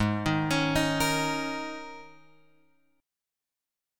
G# Suspended 2nd Suspended 4th